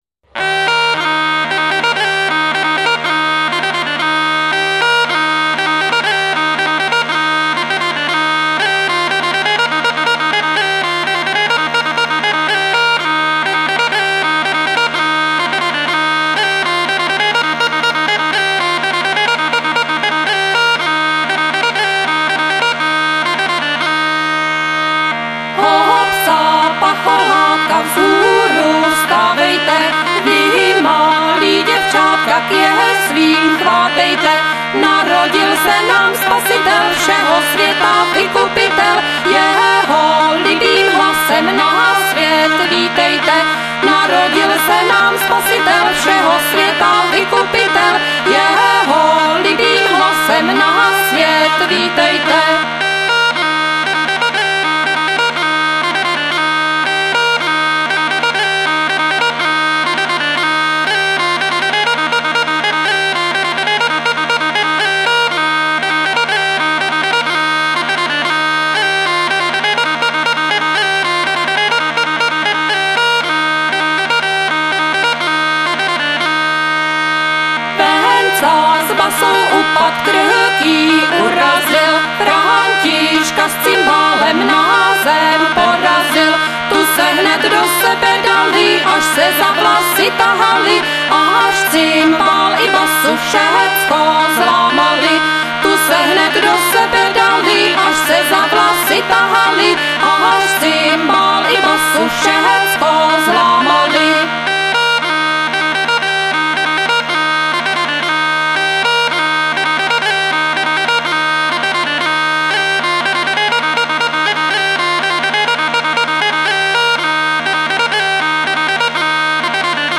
Folklorní soubor Bystřina ze Zlivi